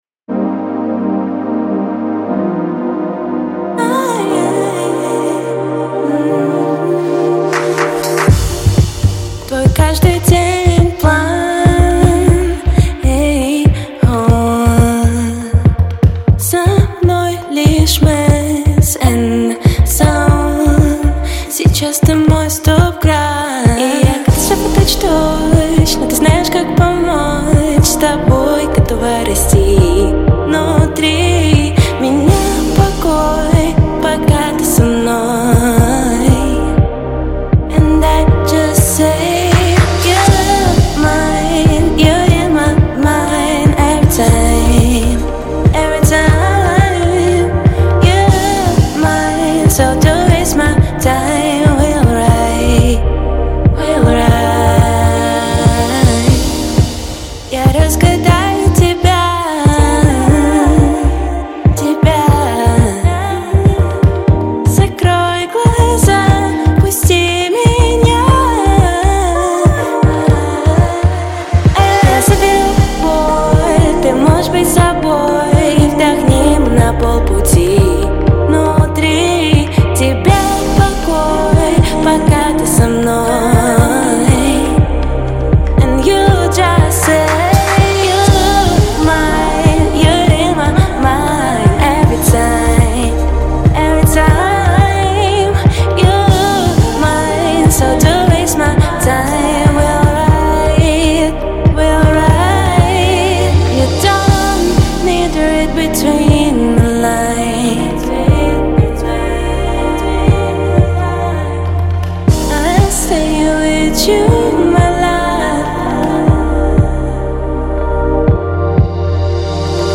between the lines - авторская песня